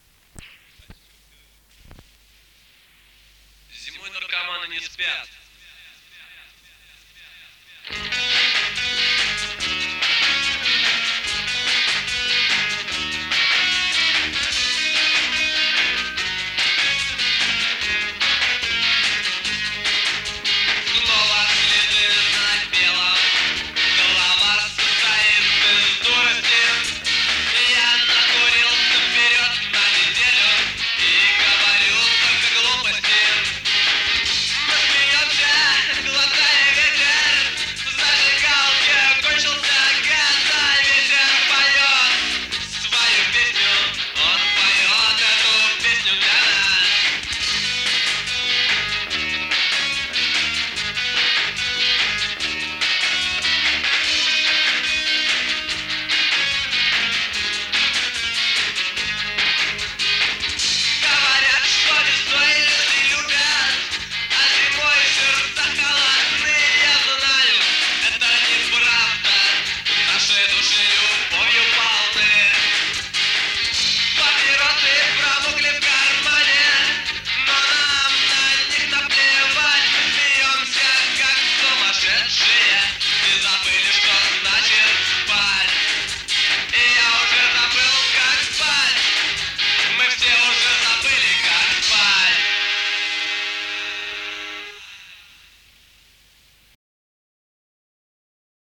Панк рок